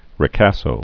(rĭ-kăsō)